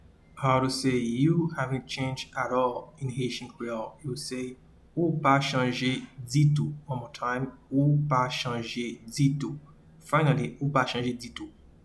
Pronunciation:
You-havent-changed-at-all-in-Haitian-Creole-Ou-pa-chanje-ditou.mp3